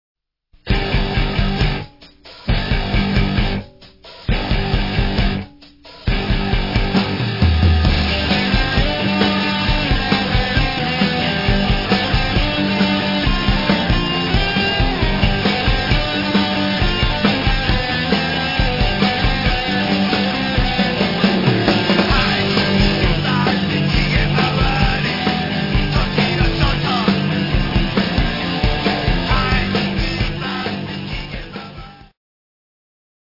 コメント 89年発表のスタジオ録音アルバム！